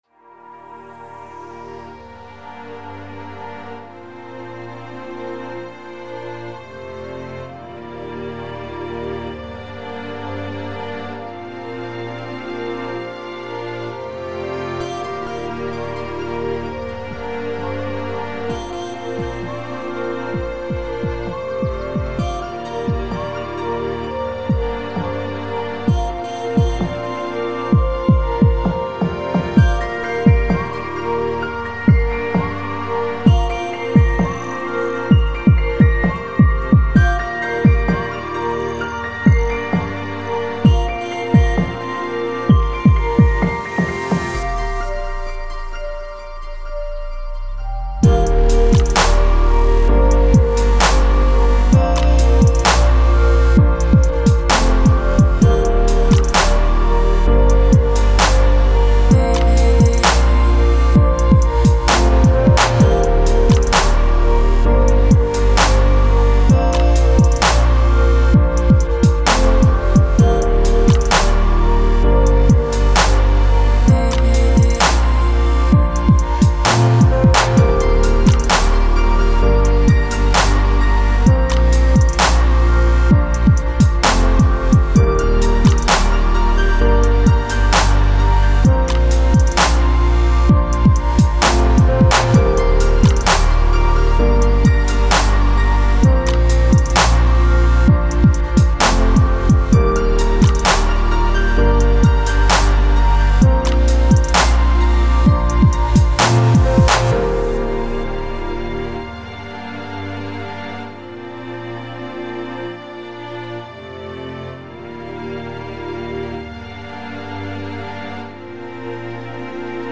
атмосферная и мечтательная композиция
сочетающая элементы инди-попа и электронной музыки.